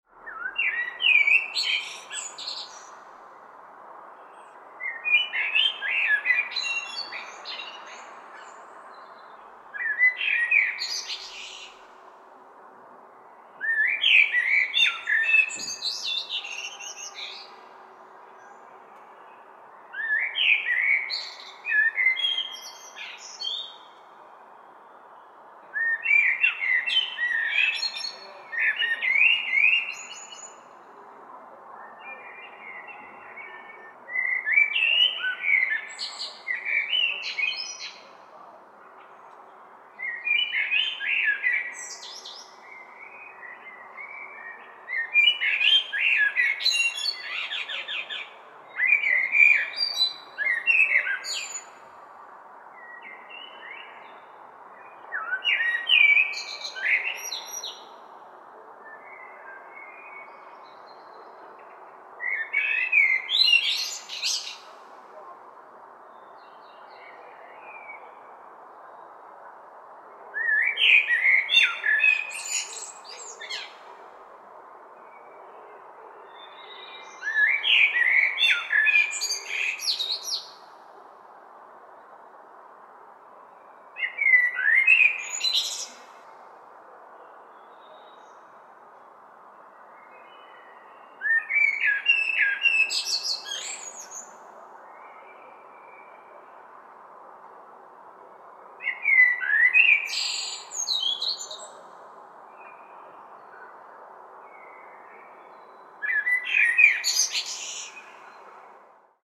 Blackbird Chirping Sound Effect
Description: Blackbird chirping sound effect. Authentic blackbird song recorded professionally in a forest environment. Bird sounds.
Blackbird-chirping-sound-effect.mp3